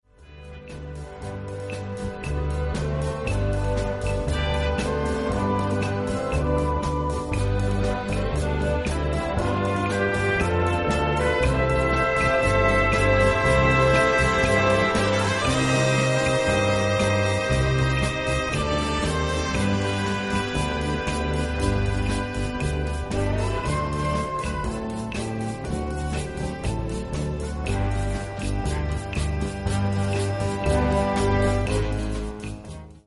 Gattung: Evergreen
Besetzung: Blasorchester